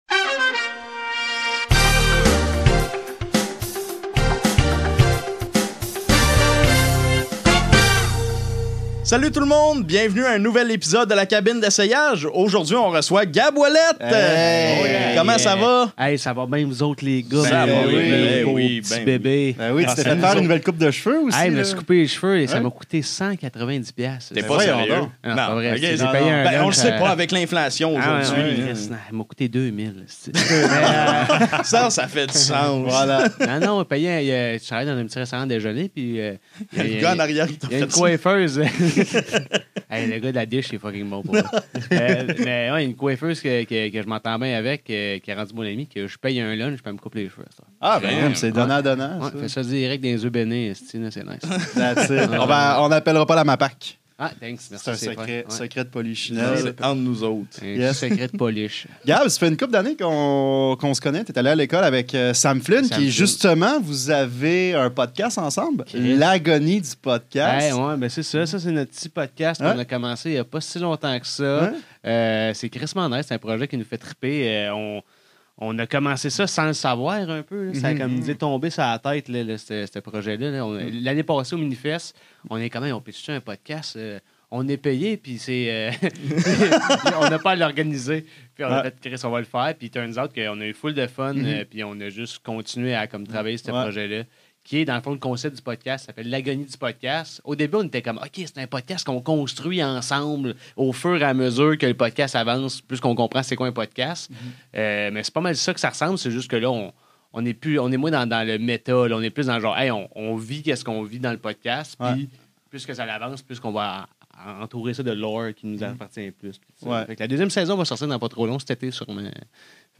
La Cabine d’Essayage est un podcast qui met l’emphase sur la création et l’improvisation. À chaque épisode, Les Piles-Poils et un artiste invité doivent présenter un court numéro sous forme de personnage (ou de stand-up) à partir d’un thème pigé au hasard.